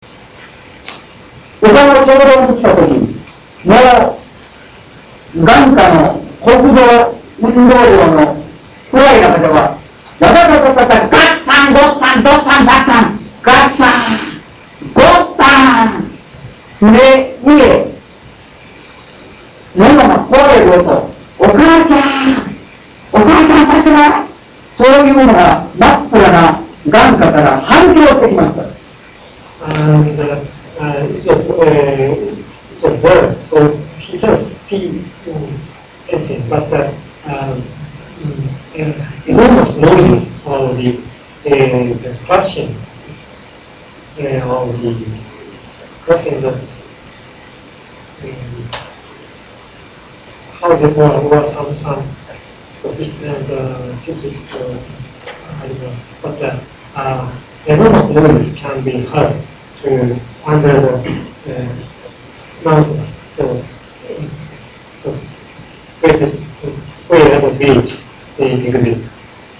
Field Trip